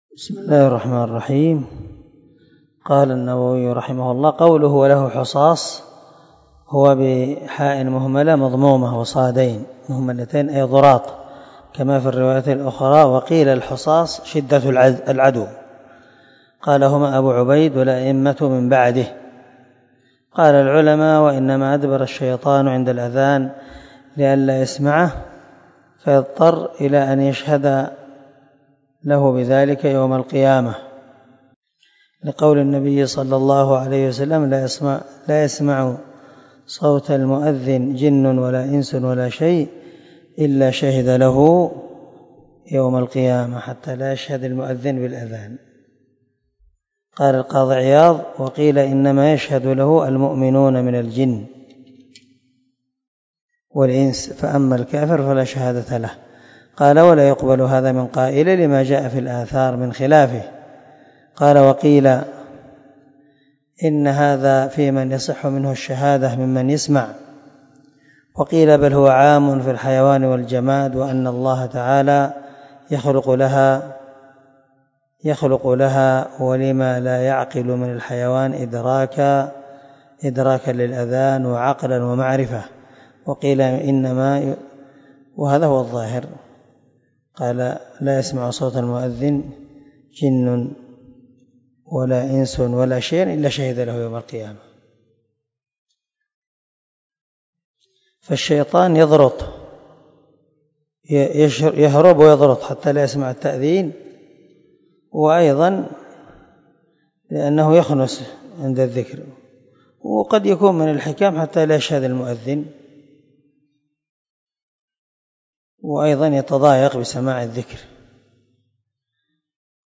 267الدرس 11 من شرح كتاب الصلاة حديث رقم ( 390 ) من صحيح مسلم